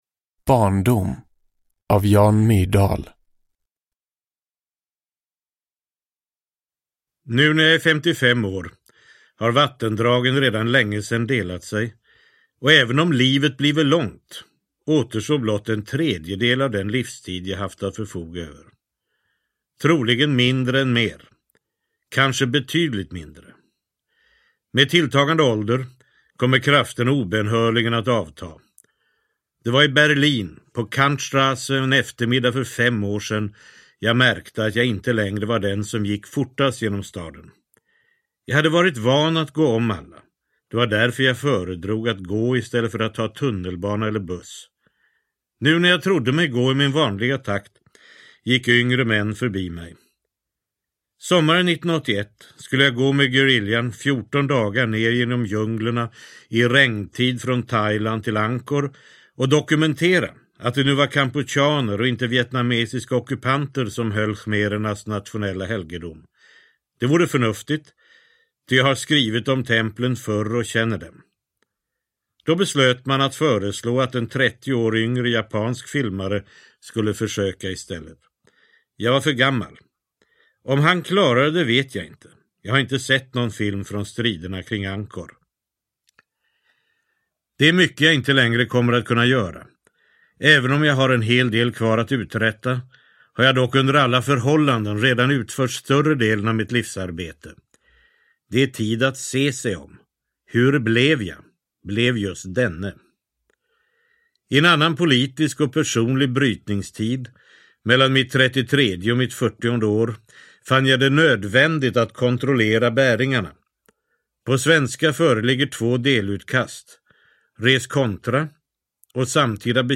Barndom – Ljudbok – Laddas ner
Uppläsare: Jan Myrdal